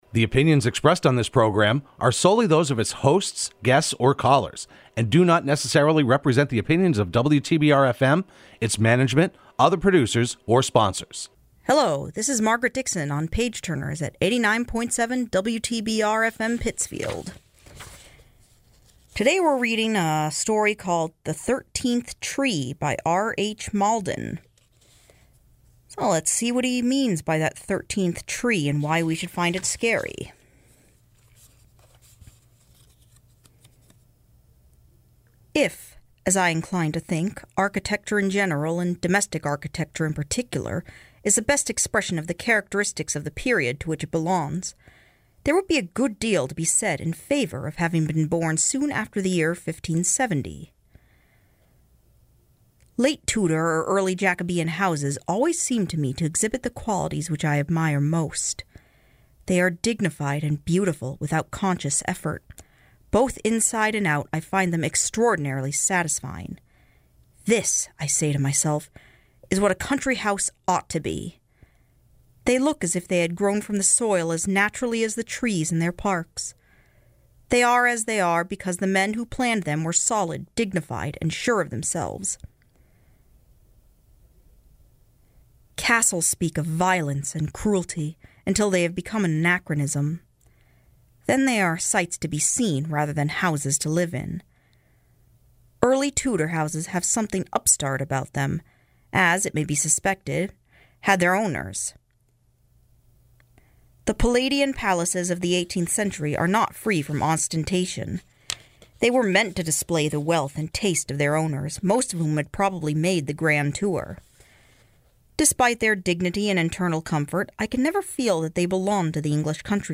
Page Turners can be heard every Sunday morning at 7am on WTBR.